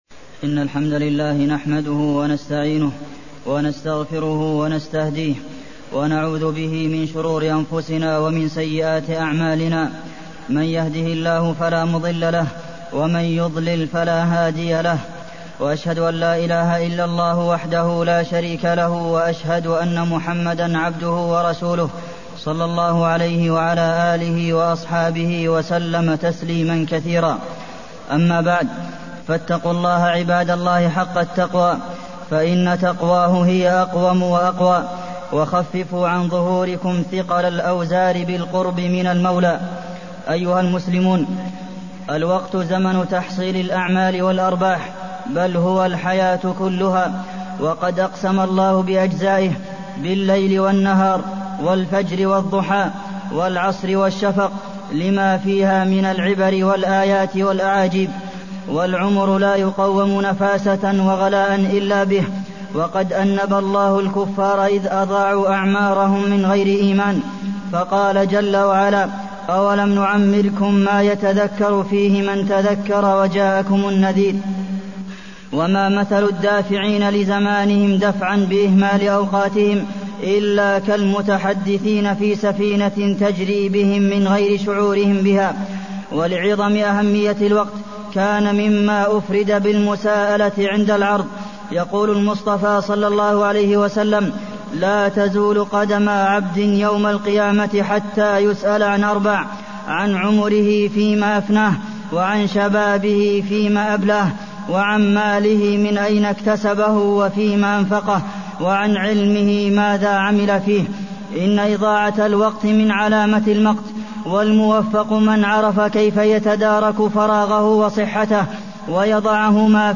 تاريخ النشر ٢٩ صفر ١٤٢١ هـ المكان: المسجد النبوي الشيخ: فضيلة الشيخ د. عبدالمحسن بن محمد القاسم فضيلة الشيخ د. عبدالمحسن بن محمد القاسم الوقت